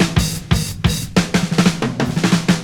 Punch Fill.wav